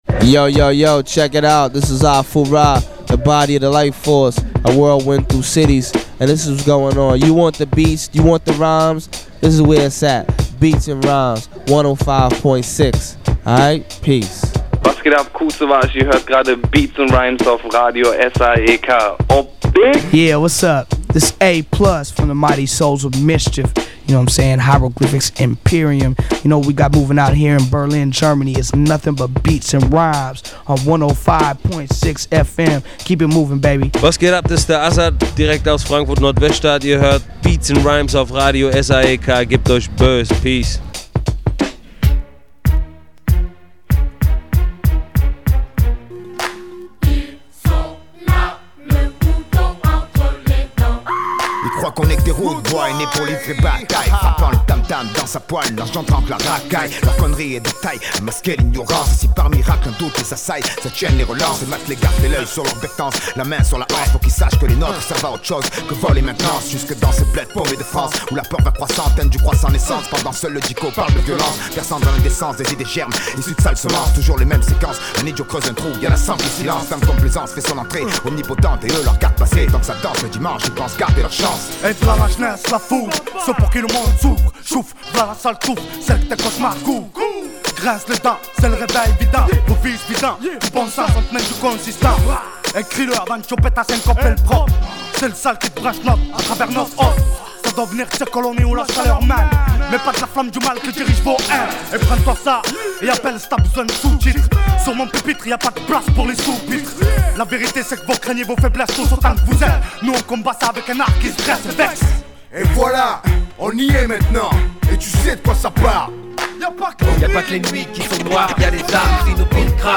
Noch ein kleiner Hinweis: Die Januar-Sendung ist die erste, welche parallel zum Radio als Livestream über die SAEK Hompage gesendet wurde.